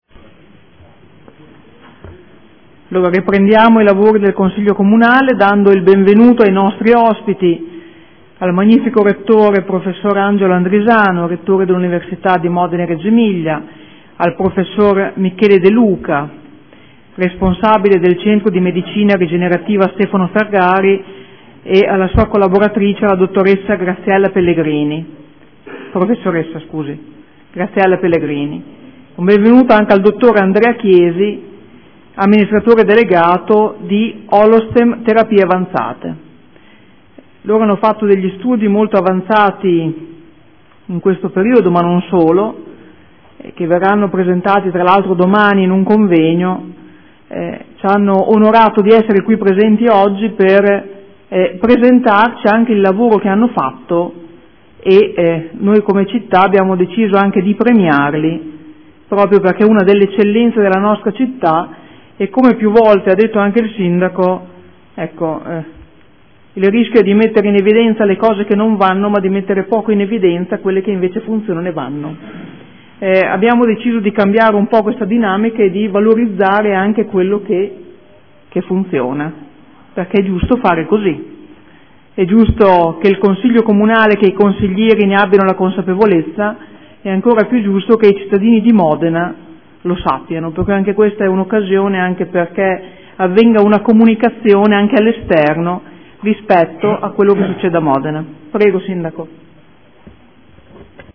Presidentessa — Sito Audio Consiglio Comunale
Seduta del 09/04/2015.